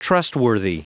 Prononciation du mot trustworthy en anglais (fichier audio)
Prononciation du mot : trustworthy